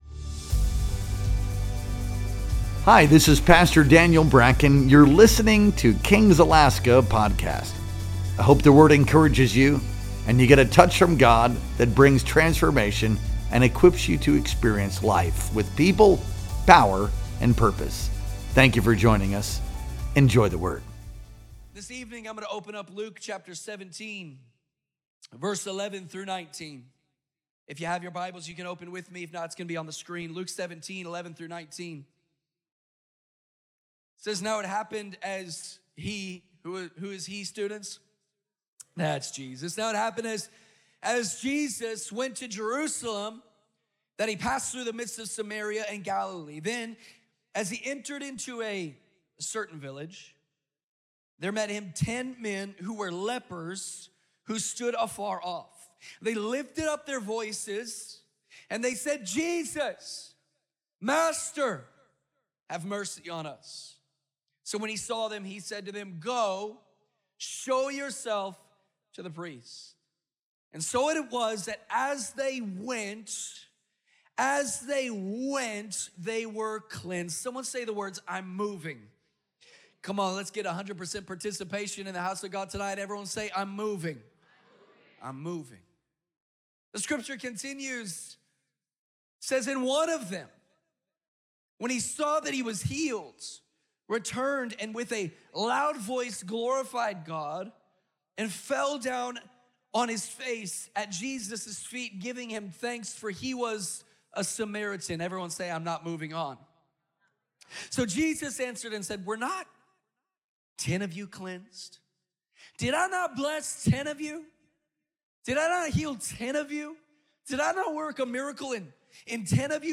Our Wednesday Night Worship Experience streamed live on June 11th, 2025.